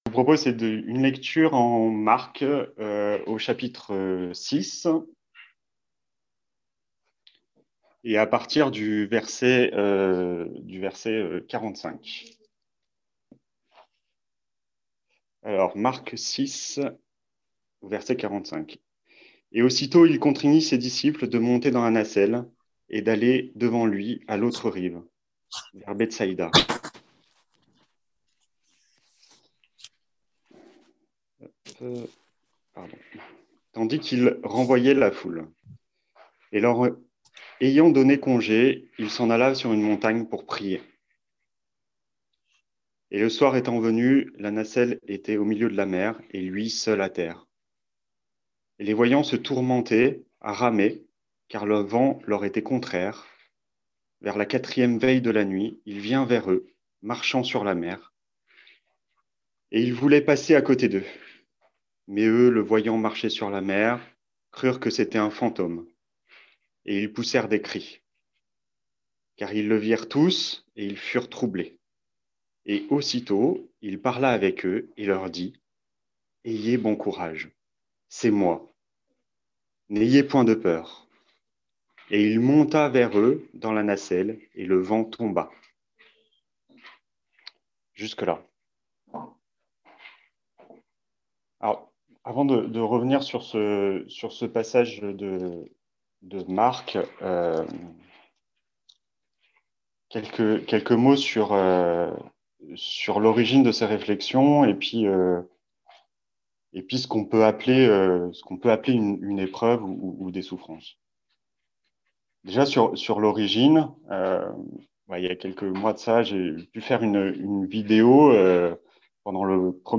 -> Ecouter le message du dimanche 29/11/2020